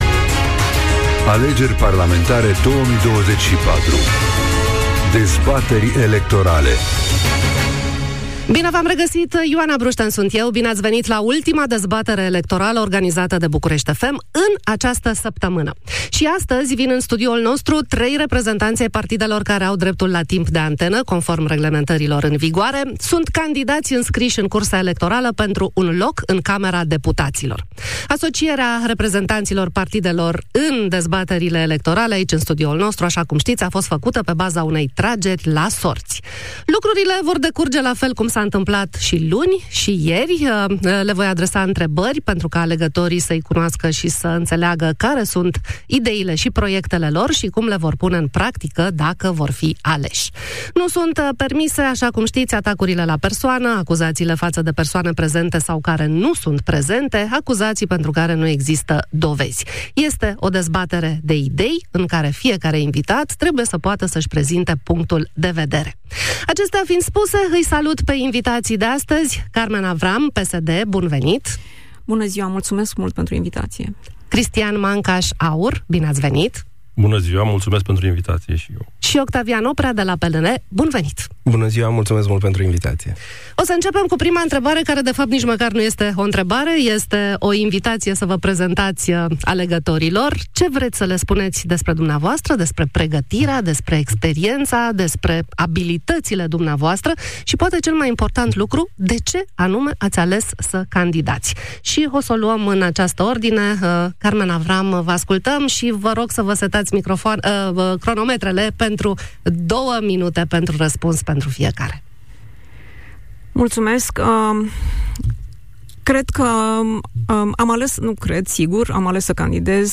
Dezbatere electorală parlamentară > Carmen Avram